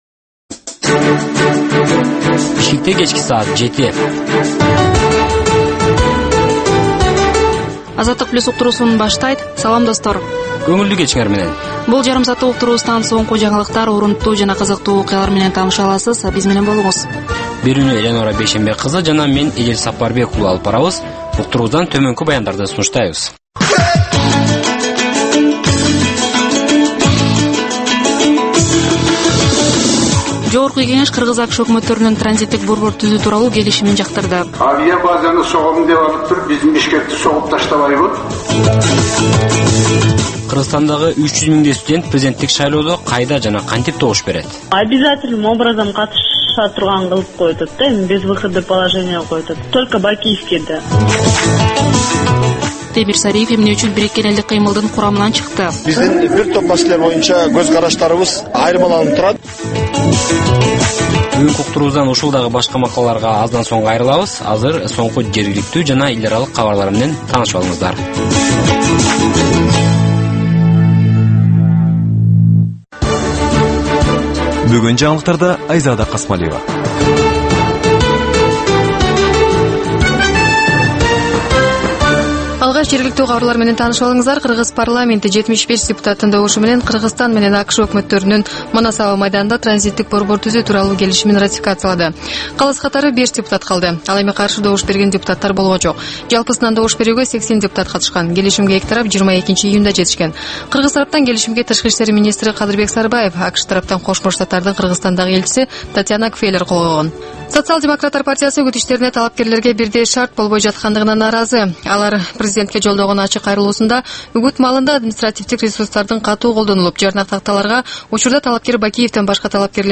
"Азаттык үналгысынын" кыргызстандык жаштарга арналган бул кечки алгачкы үналгы берүүсү жергиликтүү жана эл аралык кабарлардан, репортаж, маек, баян жана башка берүүлөрдөн турат.